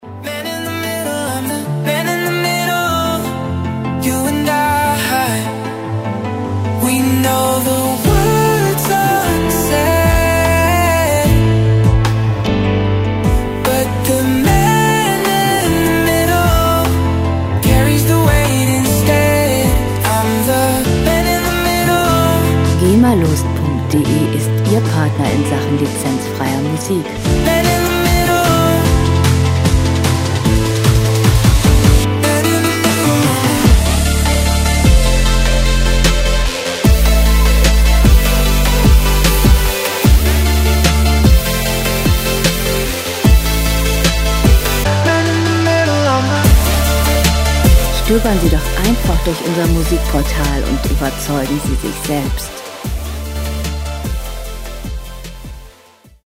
• Beat Drop Pop
dieser Beat Drop-Song erzählt von einem Mann